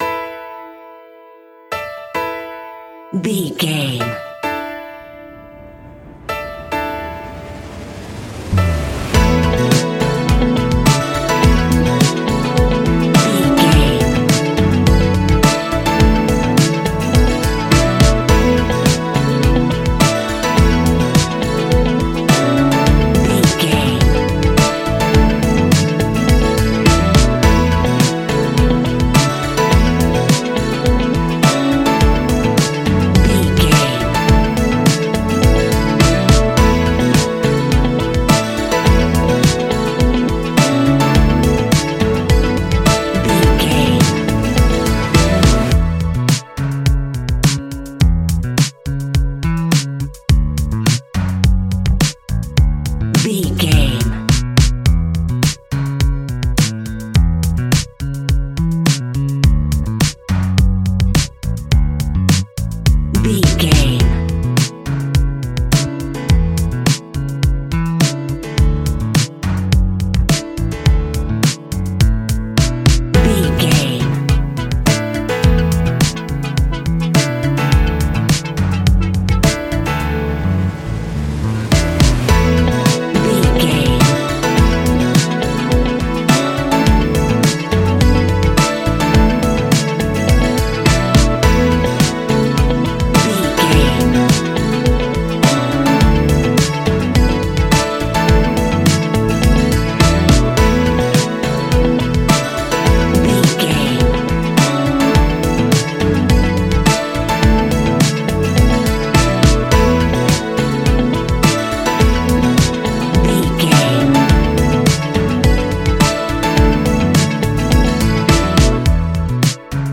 Ionian/Major
ambient
new age
downtempo
pads